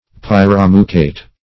pyromucate - definition of pyromucate - synonyms, pronunciation, spelling from Free Dictionary Search Result for " pyromucate" : The Collaborative International Dictionary of English v.0.48: Pyromucate \Pyr`o*mu"cate\, n. (Chem.)